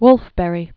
(wlfbĕrē)